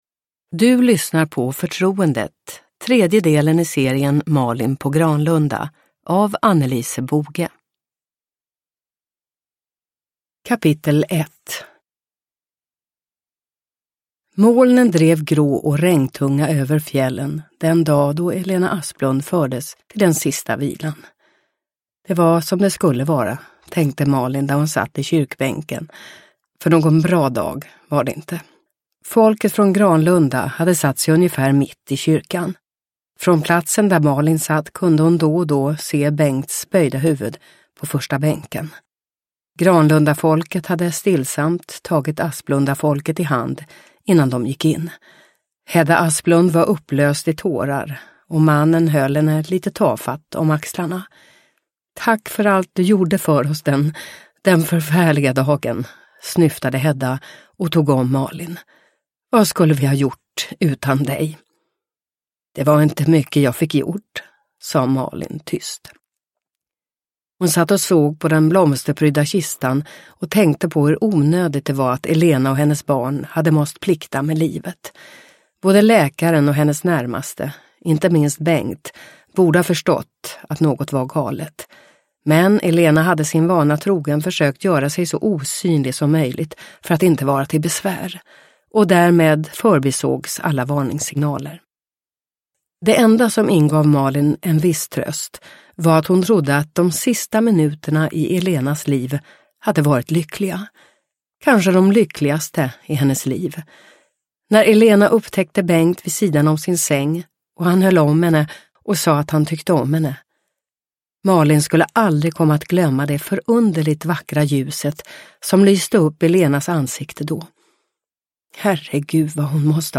Förtroendet – Ljudbok – Laddas ner